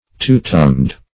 Two-tongued \Two"-tongued`\, a.
two-tongued.mp3